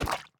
ink_sac2.ogg